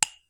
Tool Hit 9.wav